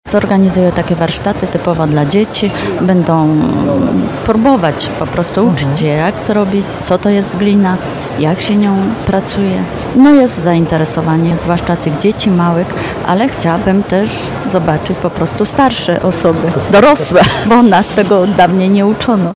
To wszystko można zobaczyć na Jarmarku Bożonarodzeniowym w Galerii Trzy Korony w Nowym Sączu. Właśnie stąd ekipa radia RDN Nowy Sącz nadawała program na żywo.